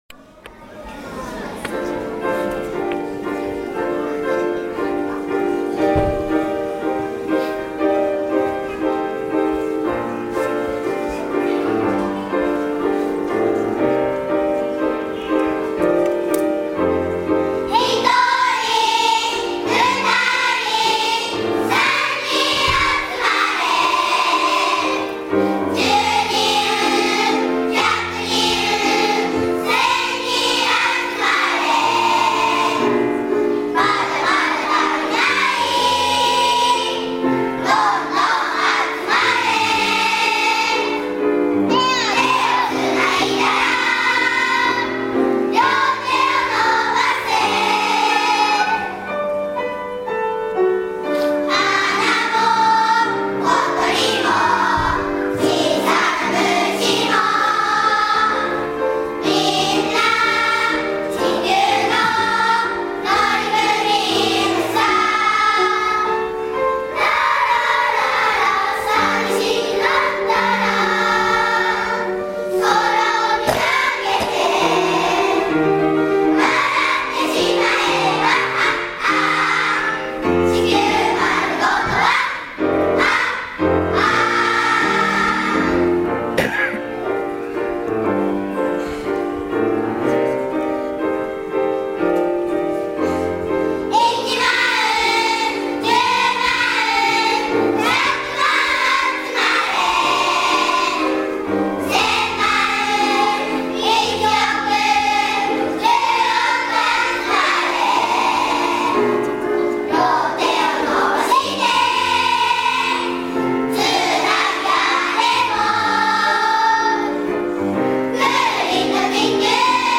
１１月１７日（土）平成３０年度校内歌声発表会が行われました。
子どもたちは練習の成果を発揮して精一杯歌いました。